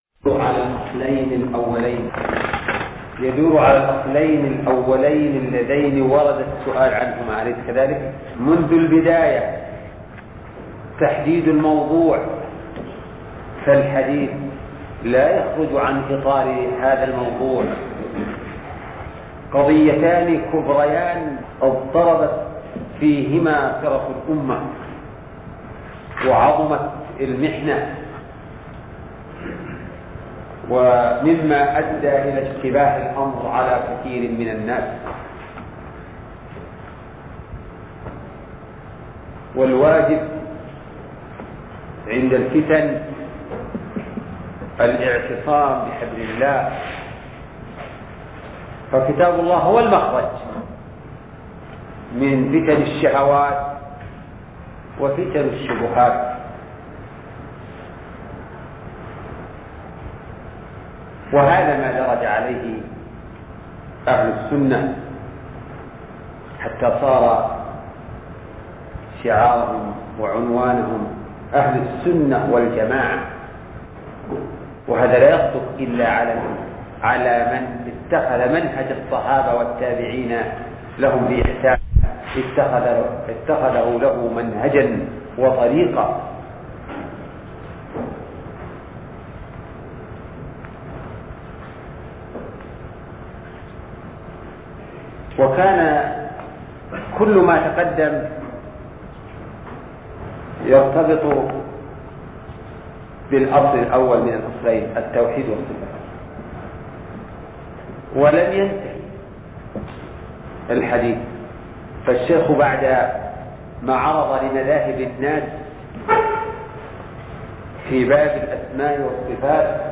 شرح الرسالة التدمرية (21) الدرس الحادي والعشرون